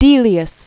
(dēlē-əs, dēlyəs), Frederick 1862-1934.